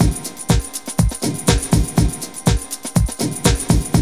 BREAKBEAT 1.wav